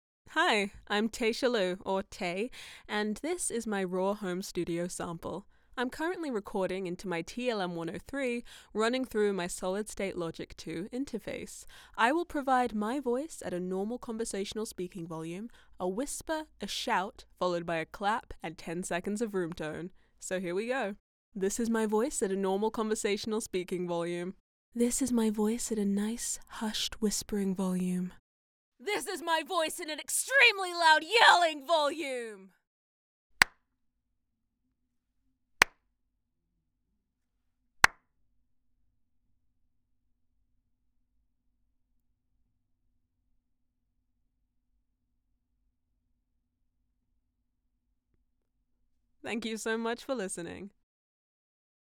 Vocal Range: Androgynous, Female Adult, Female Teen, Male Child / High to Medium-Low Voices.
HOME STUDIO SAMPLE
NEUMANN TLM103
BROADCAST QUALITY STUDIO
NOISE FLOOR -65dB average